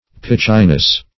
Pitchiness \Pitch"i*ness\, n.